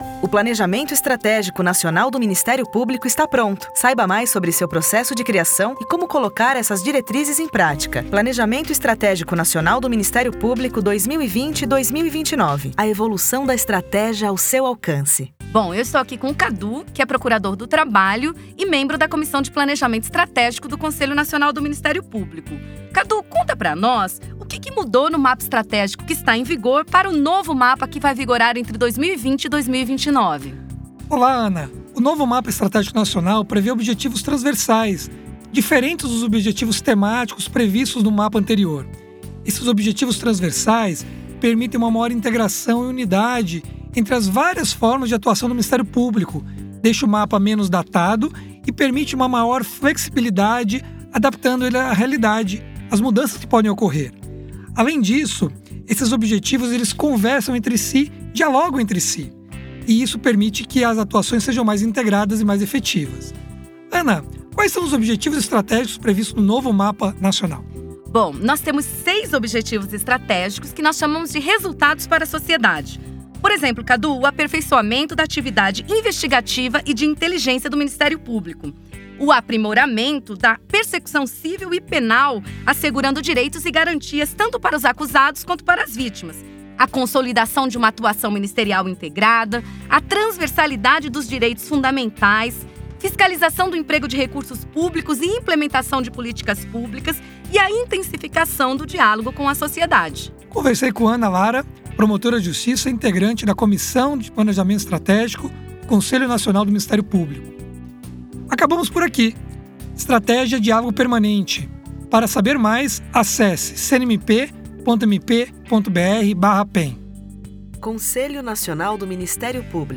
A iniciativa conta com cards para as redes sociais, gif, cartaz, e-mail marketing e podcasts com entrevistas com membros que participaram do projeto.